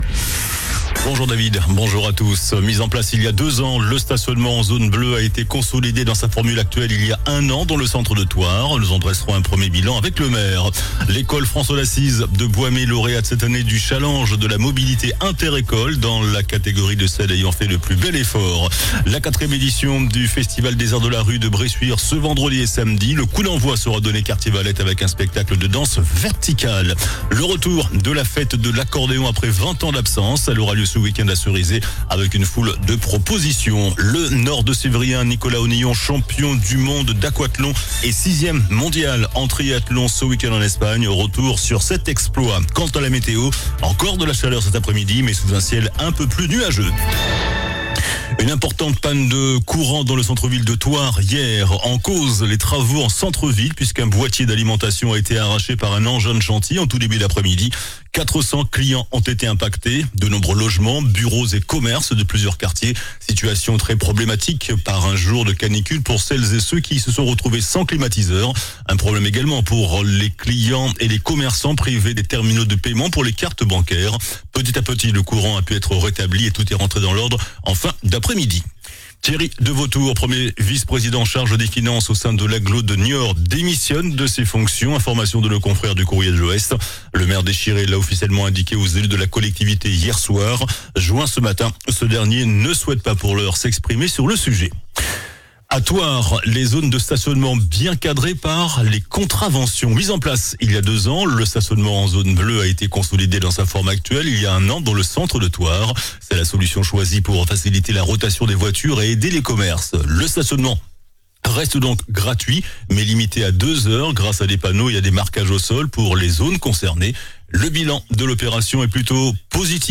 JOURNAL DU MERCREDI 02 JUILLET ( MIDI )